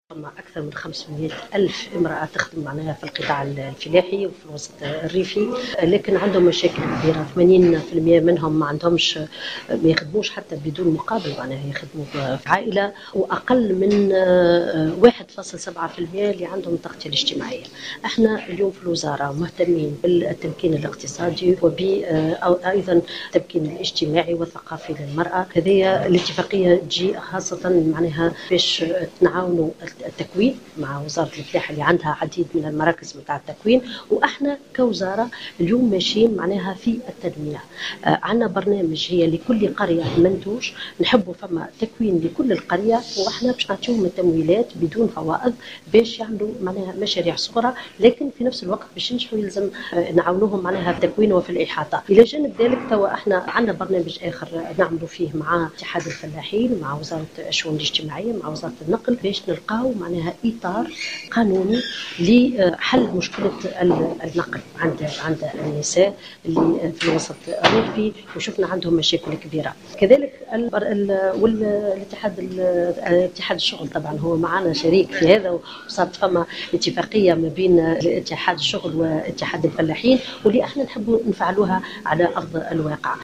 وأفادت وزيرة المرأة سميرة مرعي في تصريح